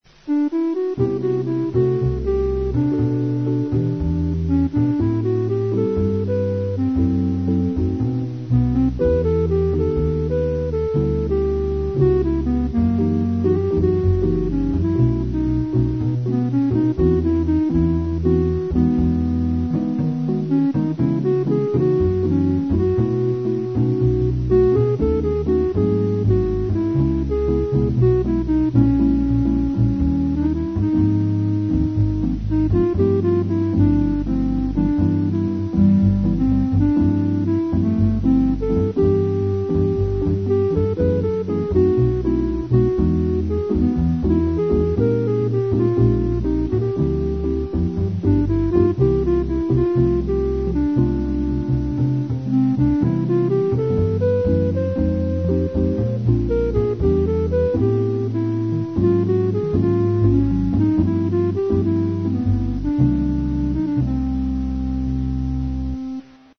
(Bb, Eb, Concert)